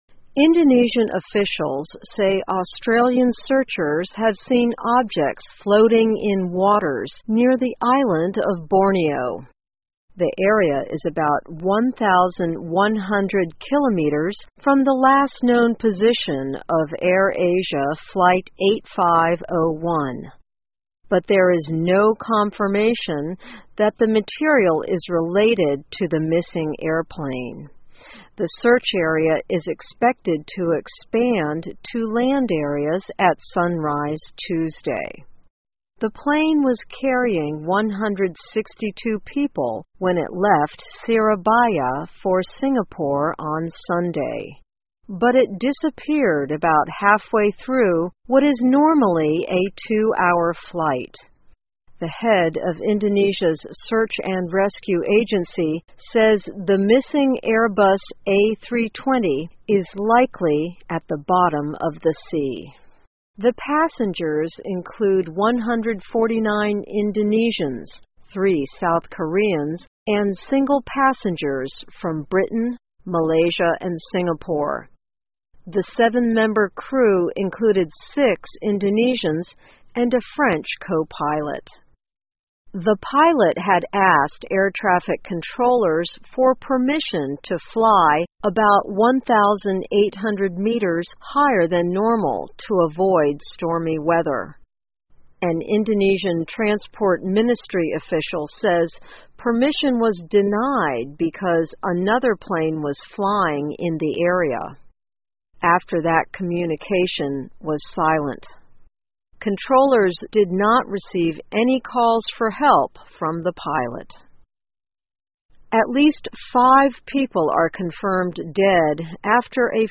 VOA慢速英语2015 印尼称失踪亚航飞机可能落入海底 听力文件下载—在线英语听力室